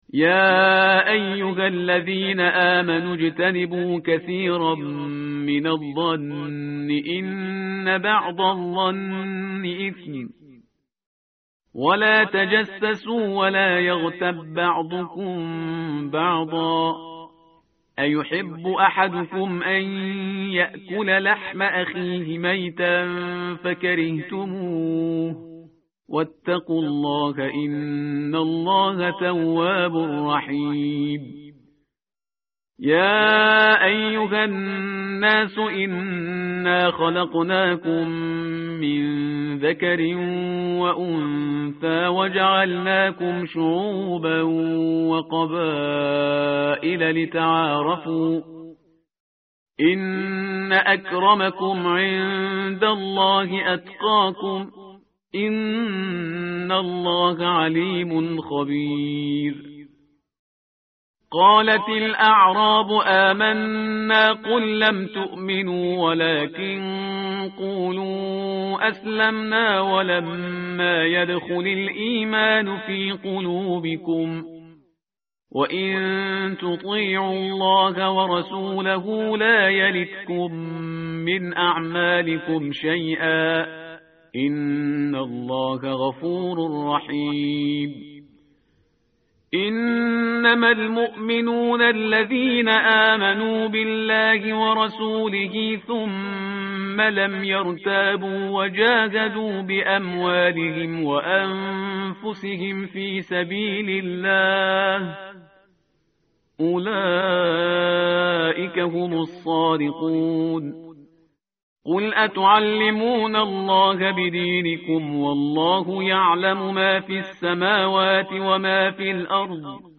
tartil_parhizgar_page_517.mp3